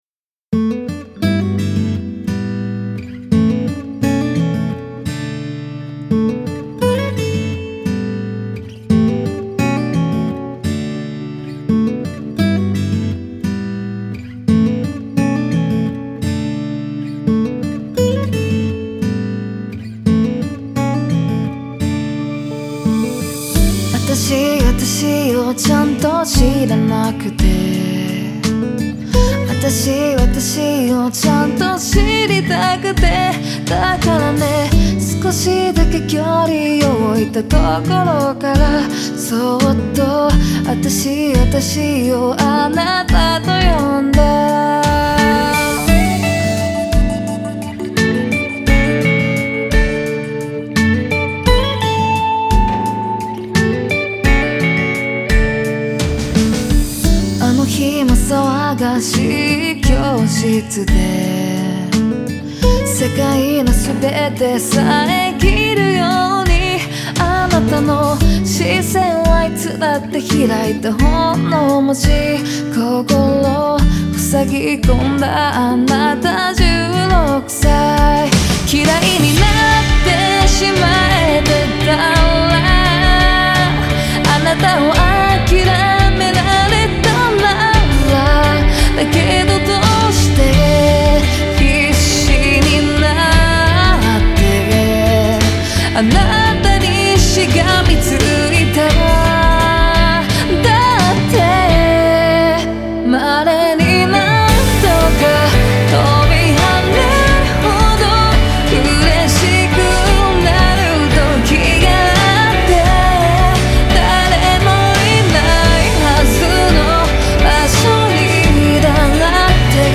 ロックやブラック・ミュージックからも影響を受けたサウンドを切ない歌声に乗せて。
京都 西陣出身のシンガーソングライター。